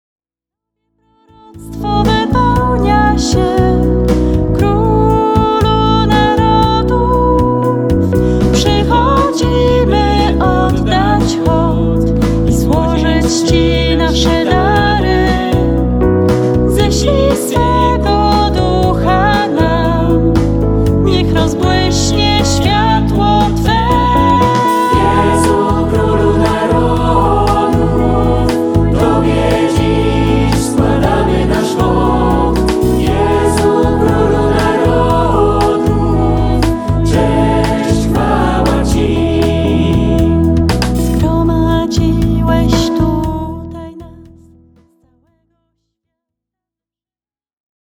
De très beaux chants d'assemblée